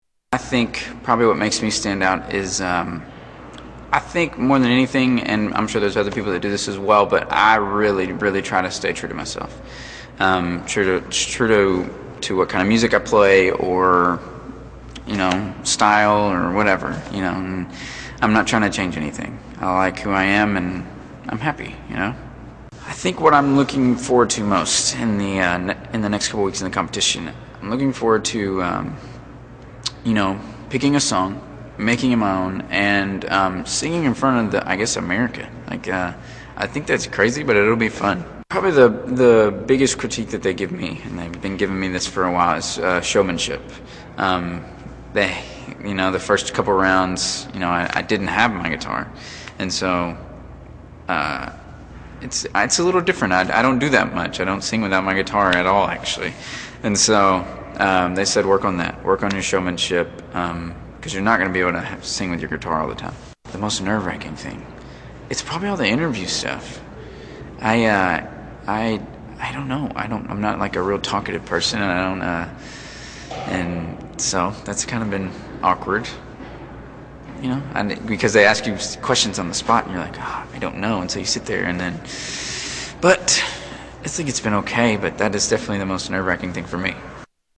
Kris Allen Interview